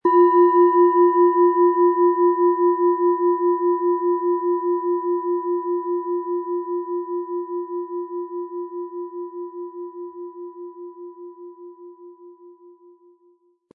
Planetenton
Wasser
Wie klingt diese tibetische Klangschale mit dem Planetenton Wasser?
SchalenformOrissa
MaterialBronze